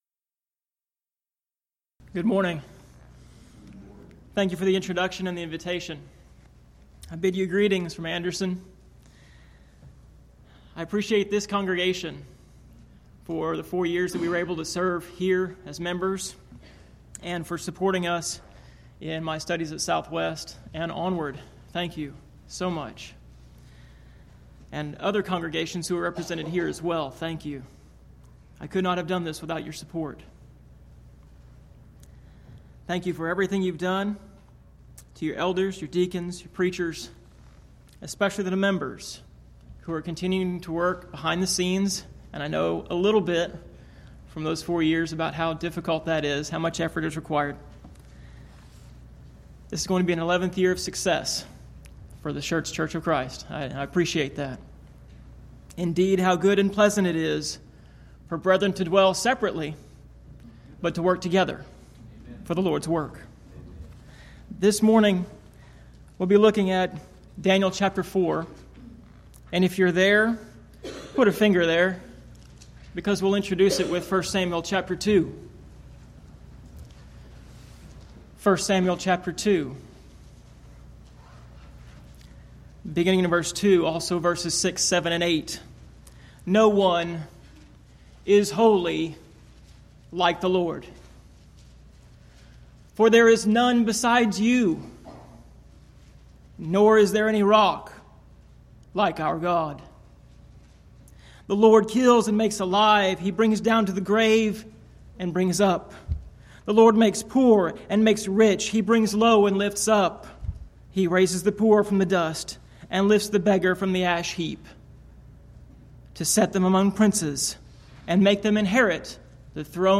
Event: 11th Annual Schertz Lectures Theme/Title: Studies in Daniel